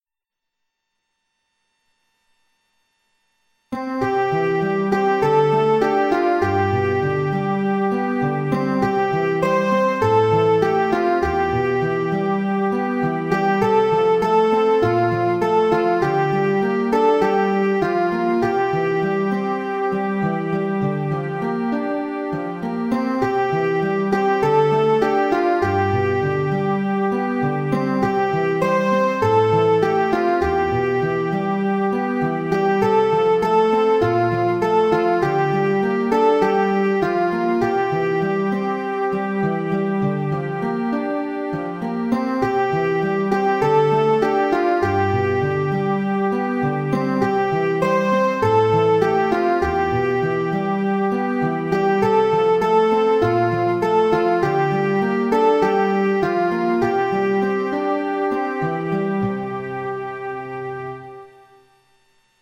A quiet devotion to our faithful God.